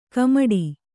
♪ kamaḍi